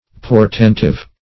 Portentive \Por*tent"ive\, a.
portentive.mp3